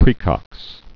(prēkŏks)